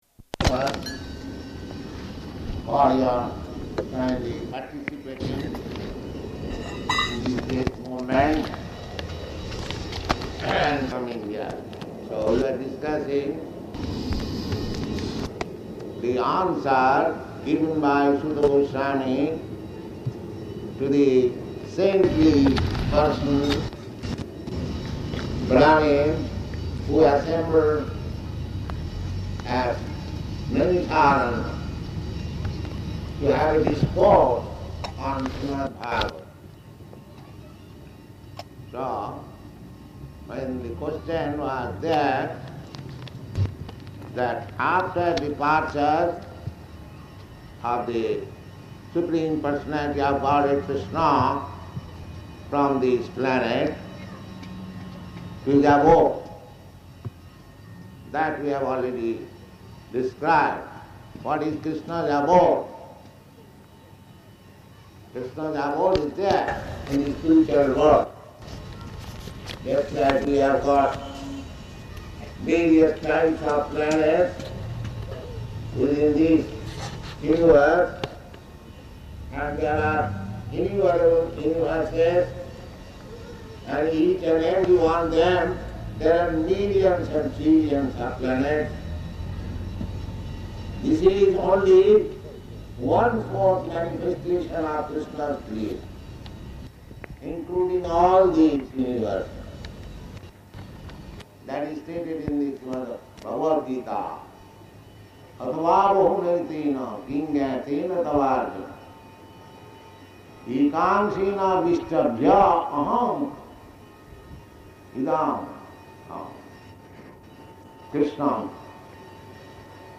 Śrīmad-Bhāgavatam 1.2.5 [partially recorded]
Location: Bombay